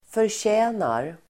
Uttal: [förtj'ä:nar]